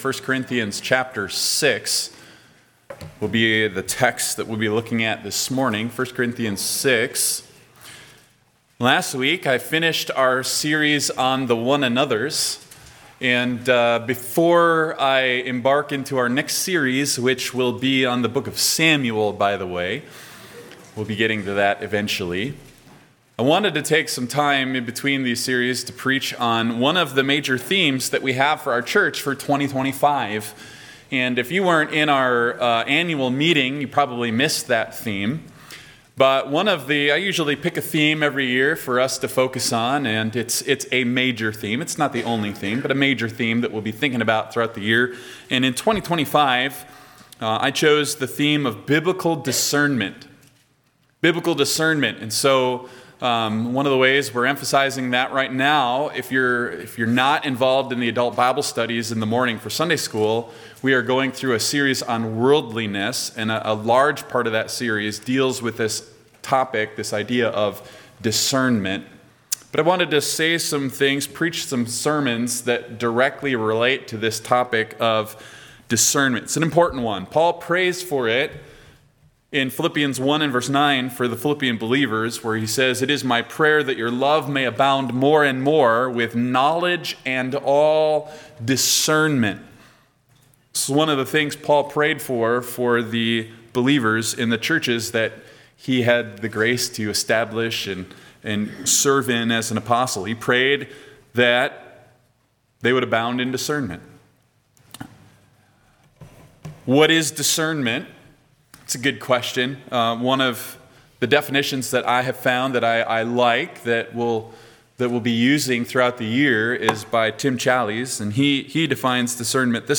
Sermons by Faith Baptist Church